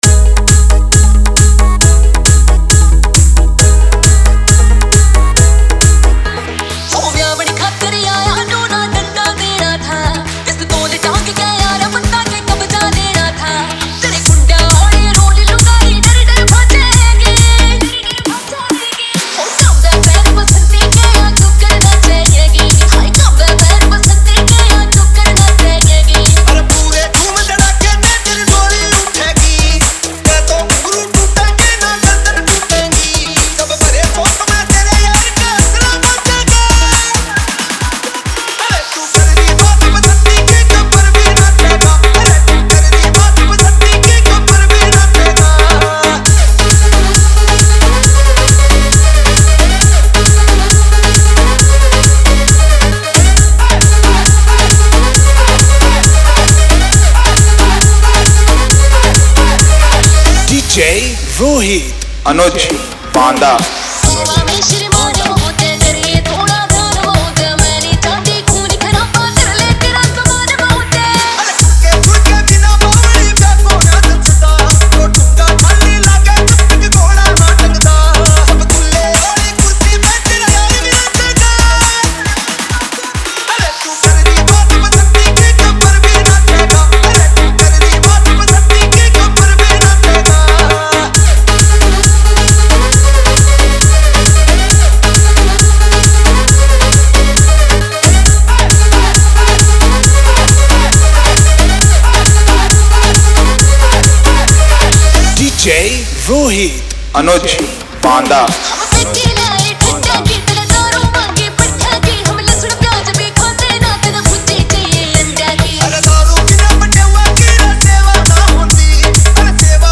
Bollywood Bhojpuri Allahabad Remixer Zone